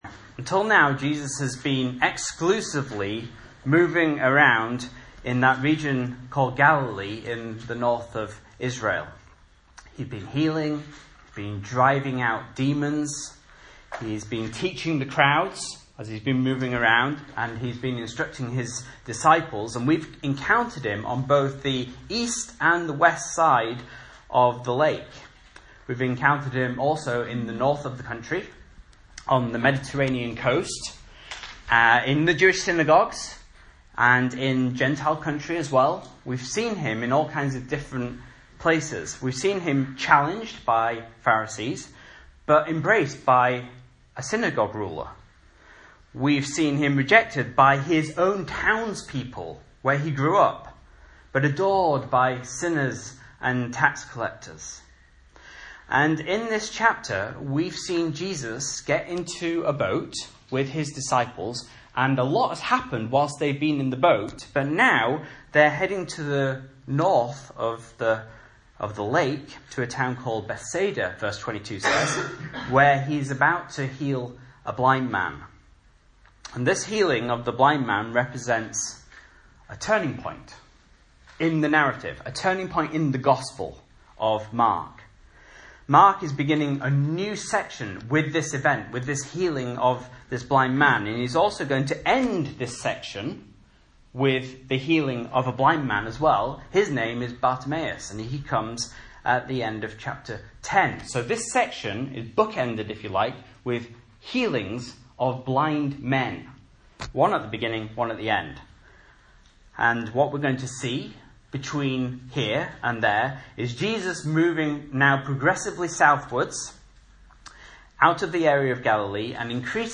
Message Scripture: Mark 8:22-38 | Listen